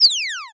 minimize_005.ogg